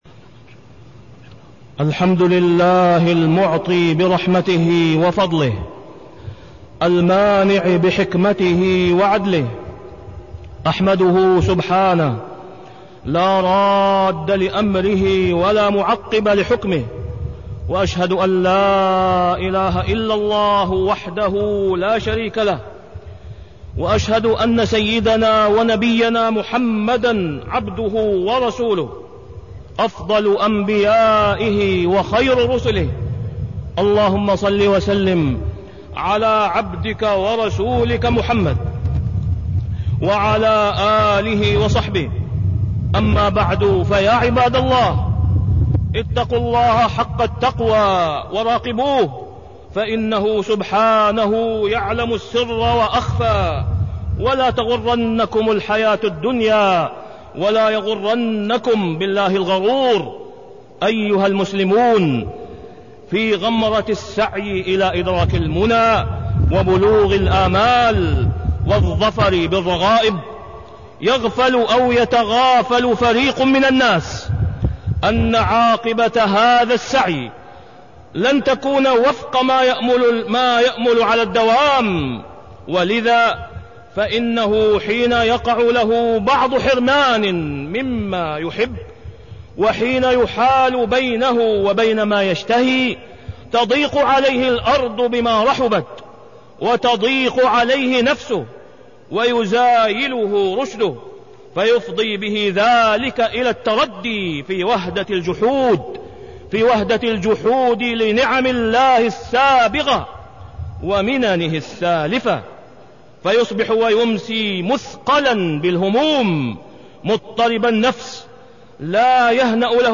تاريخ النشر ٢٤ جمادى الآخرة ١٤٢٤ هـ المكان: المسجد الحرام الشيخ: فضيلة الشيخ د. أسامة بن عبدالله خياط فضيلة الشيخ د. أسامة بن عبدالله خياط هوان الدنيا